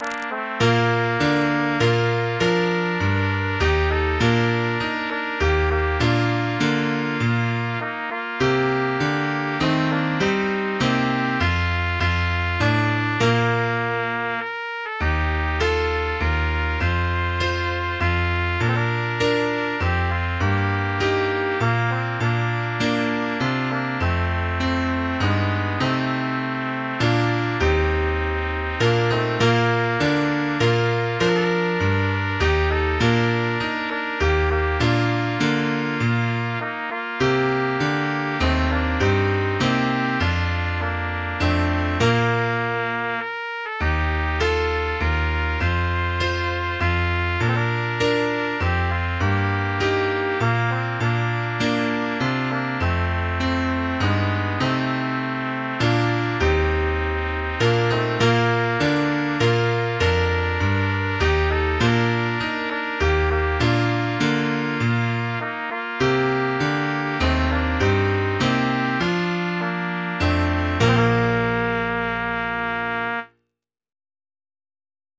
MIDI 4.6 KB MP3 (Converted) 1.15 MB MIDI-XML Sheet Music